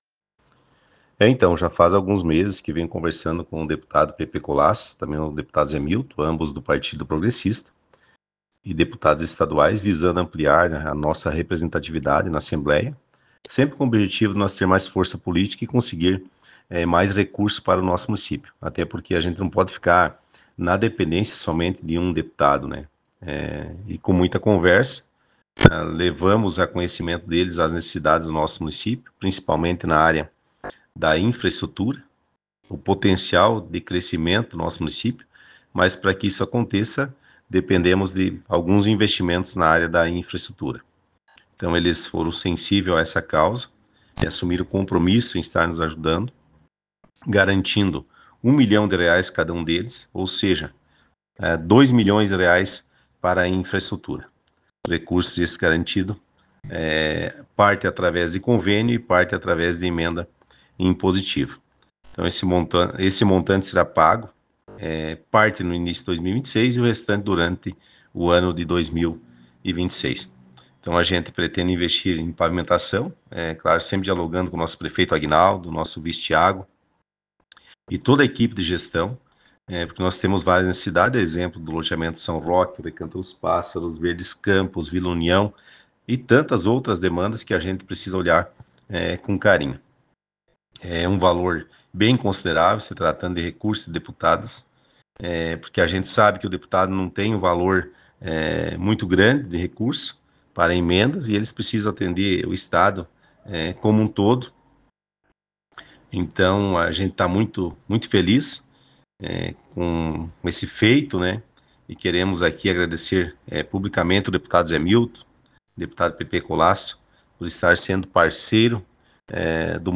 Secretário_de_Infraestrutura_de_Capinzal,_Jairo_Luzi_Hoffmann..ogg